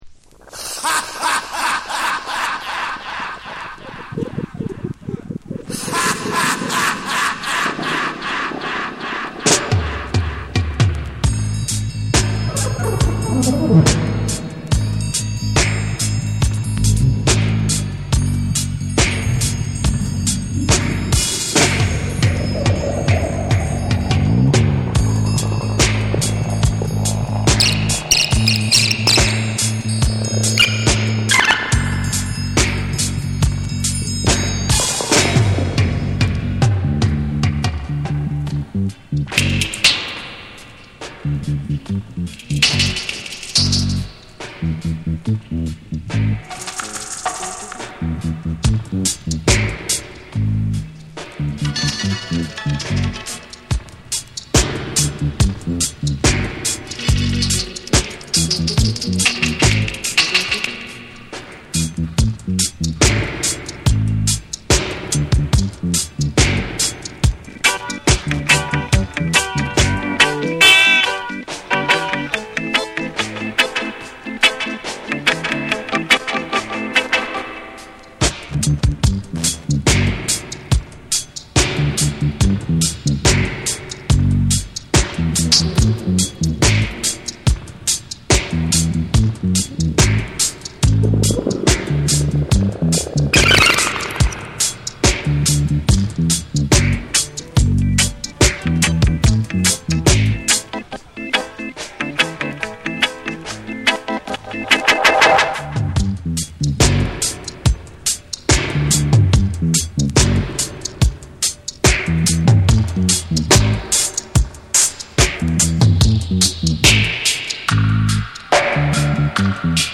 エコーとリバーブが縦横無尽に飛び交い、闇とユーモアが同居する音響世界を展開する。
REGGAE & DUB